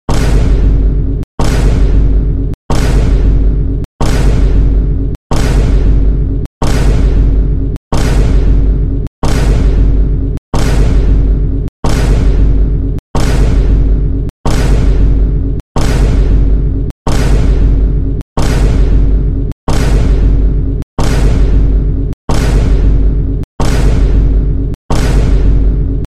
20 Vine Boom Sound Effects Téléchargement d'Effet Sonore